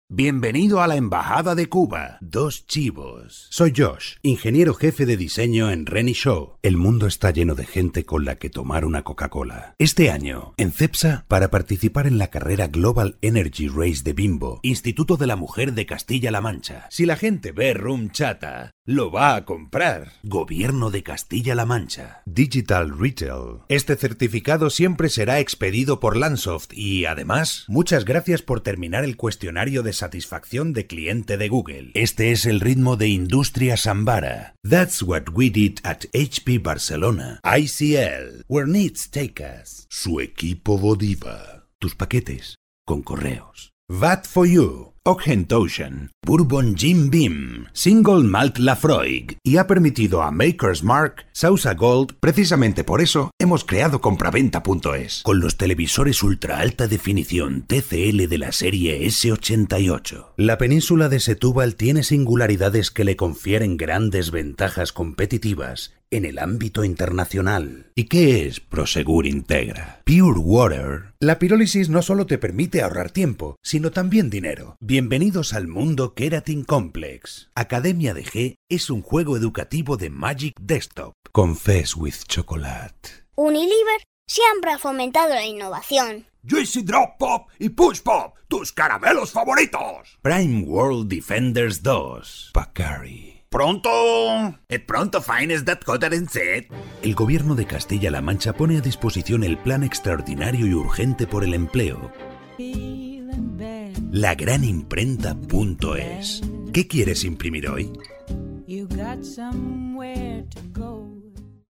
Native speaker Male 50 lat +
Nagranie lektorskie